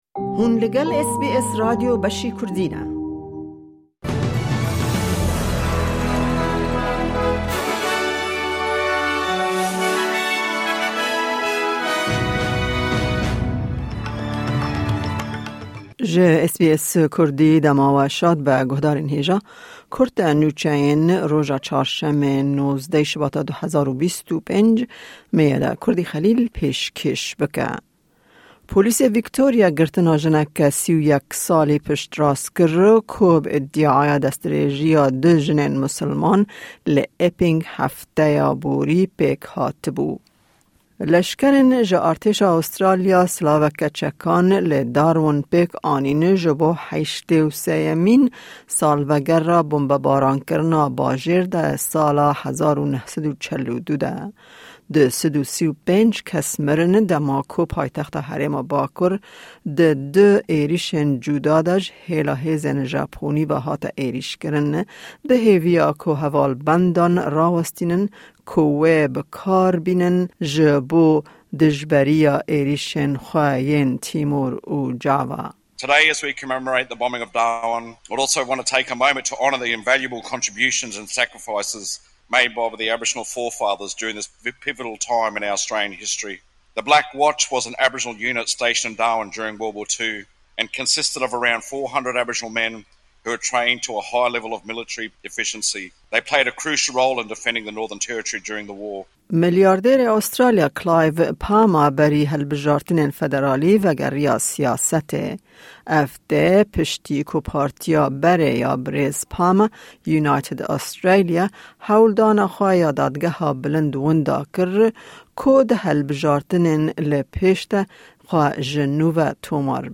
Kurte Nûçeyên roja Çarşemê,19î Şibata 2025